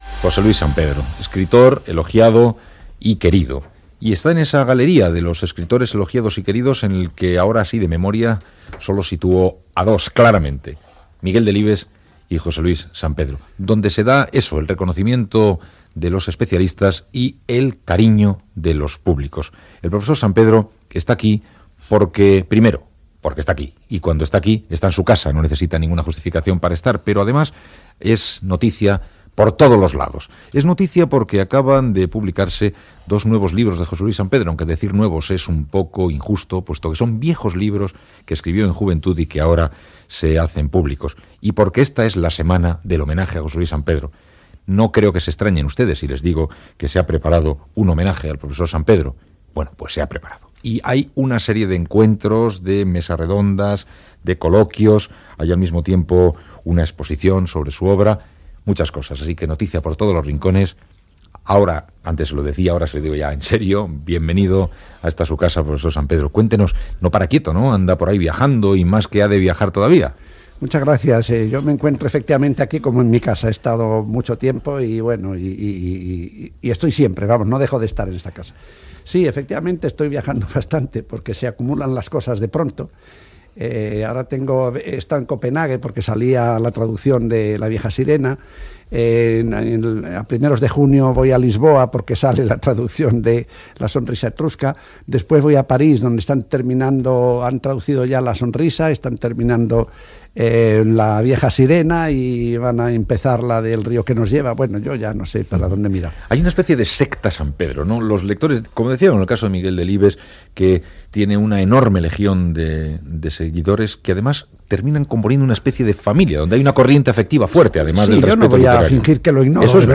Entrevista a l'escriptor José Luis Sampedro que parla sobre com escriu i dels seus llibres